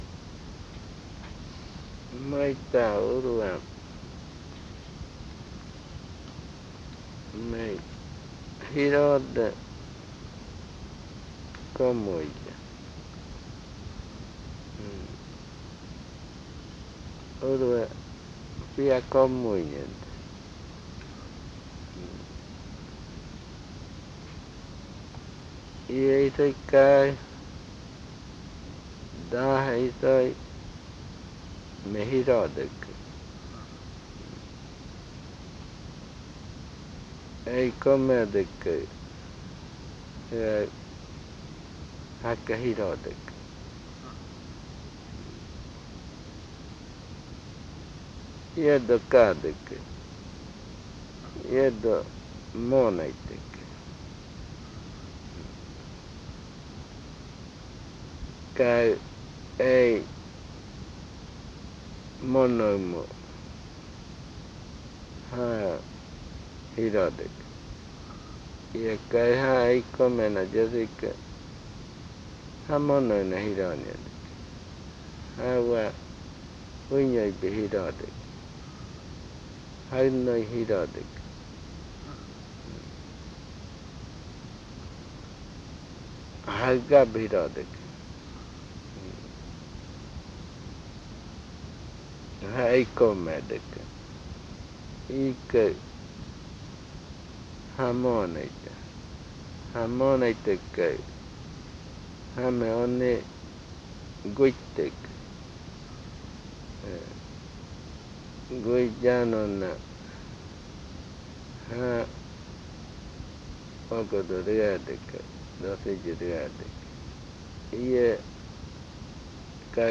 Se pueden reconocer cuatro partes en esta narración.